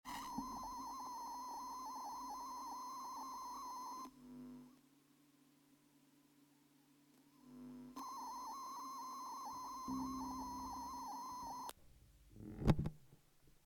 Kombiniert man die beiden, tritt ein hohes Störgeräusch auf.
Anbei mal eine Aufnahme, auf der man das Problem hört. ACHTUNG nicht zu laut aufdrehen! Da, wo es zwischenzeitig ruhig ist, habe ich den Volumeregler um vielleicht 10% zurück gedreht, wirklich nicht viel.
Anhänge MxrEnvelopeFilter.mp3 320,1 KB